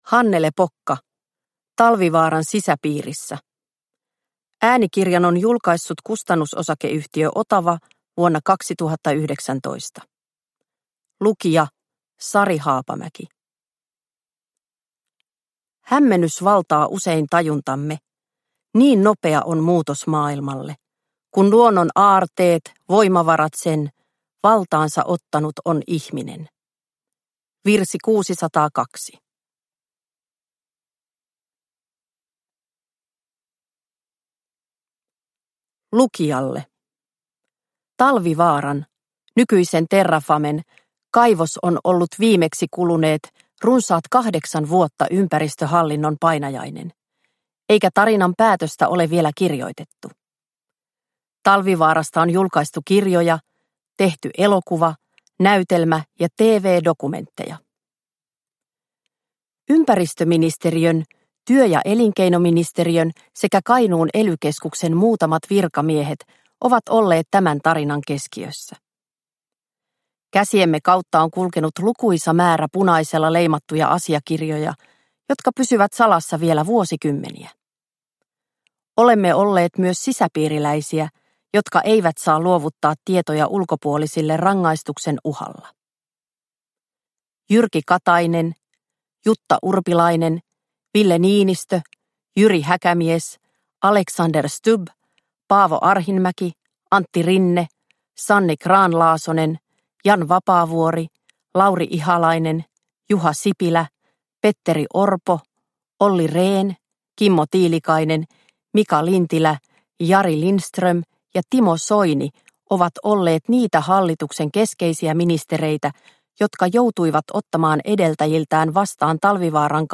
Talvivaaran sisäpiirissä – Ljudbok – Laddas ner